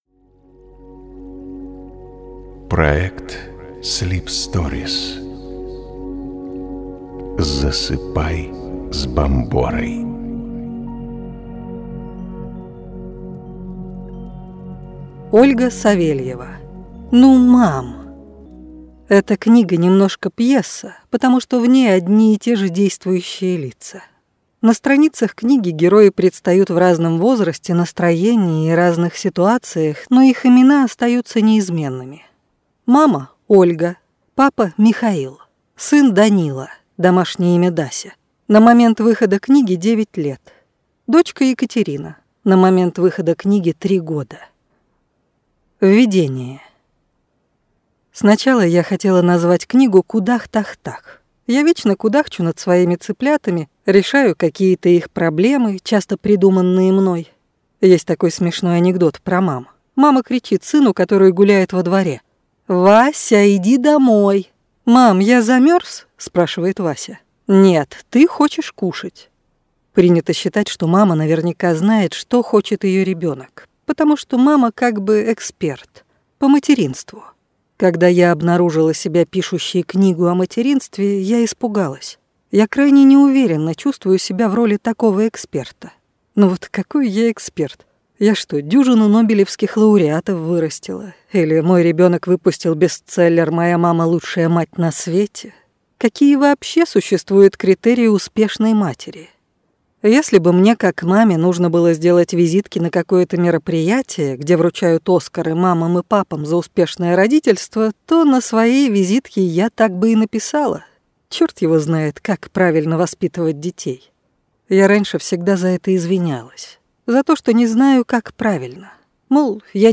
Аудиокнига Ну ма-а-ам!